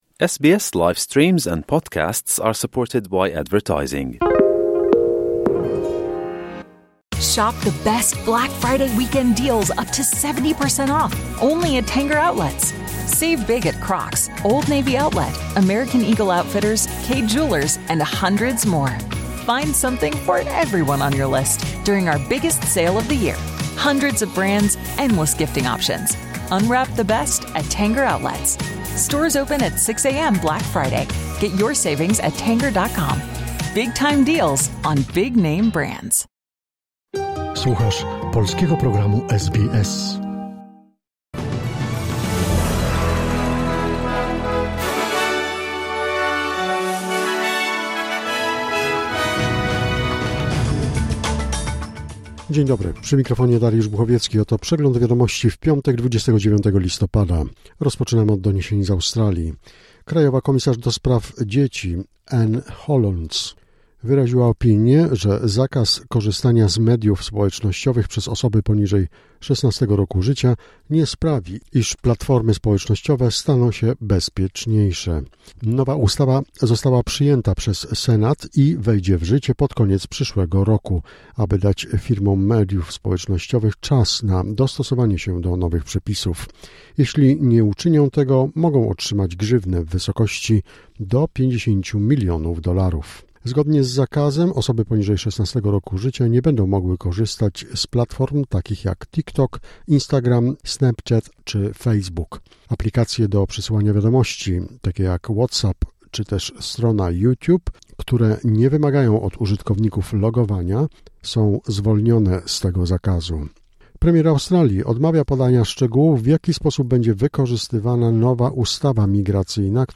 Wiadomości 29 listopada SBS News Flash